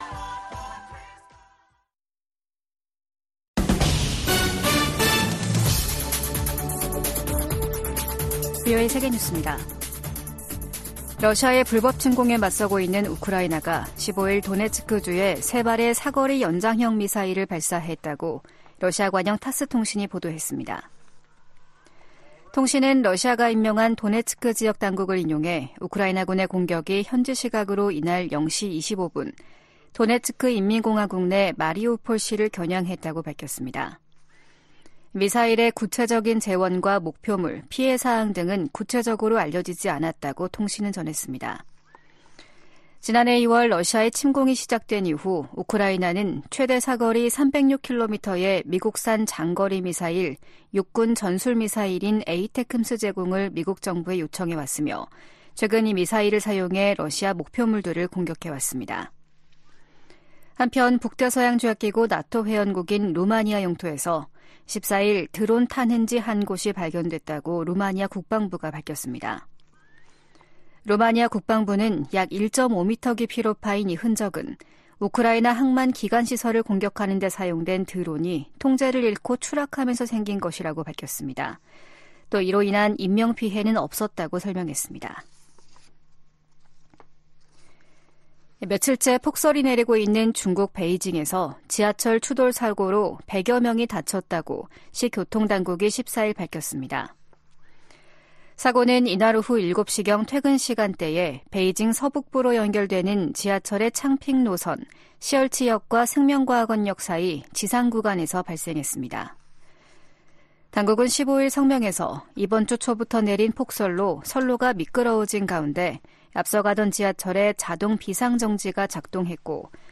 VOA 한국어 아침 뉴스 프로그램 '워싱턴 뉴스 광장' 2023년 12월 16일 방송입니다. 미국 정부가 북한과의 외교 추진 방침에 변함이 없다고 밝혔습니다. 내년도 회계연도 미국 국방 정책의 방향과 예산을 설정한 국방수권법안이 의회를 통과했습니다.